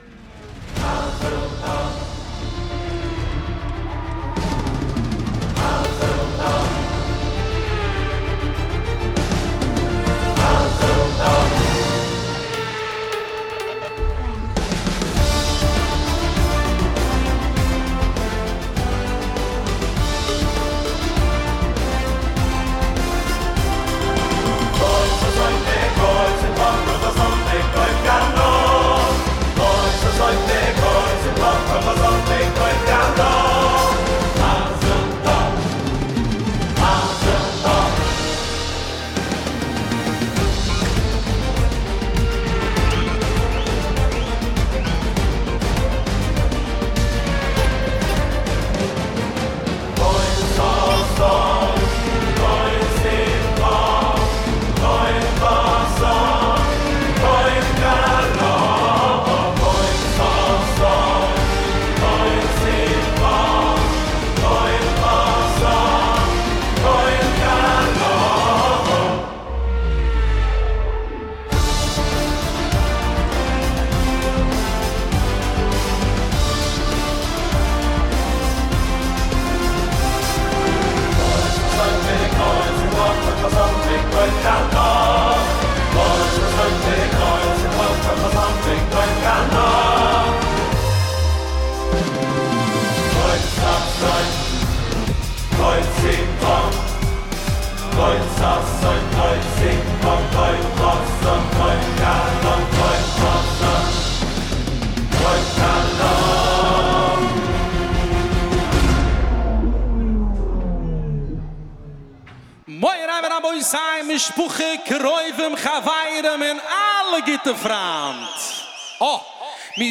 מקצבים שבניתי על קורג.